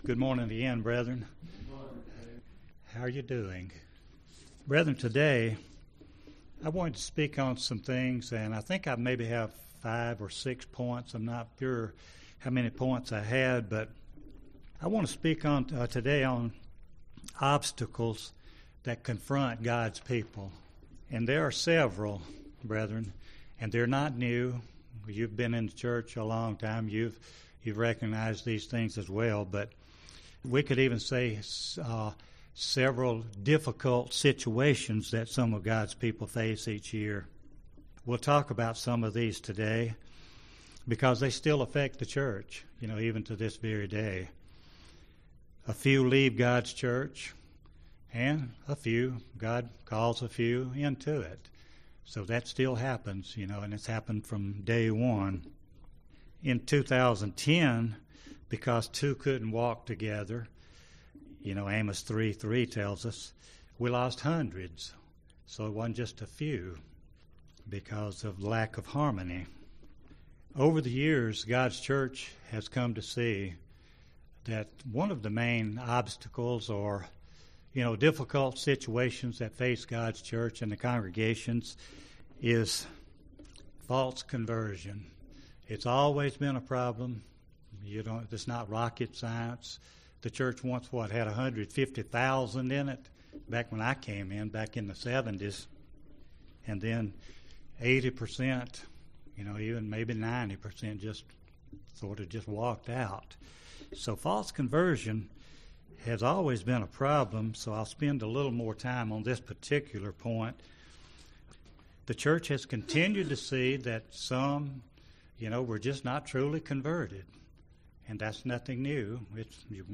This sermon points out several obstacles or stumbling blocks that some in the Church run into each year.
Given in Gadsden, AL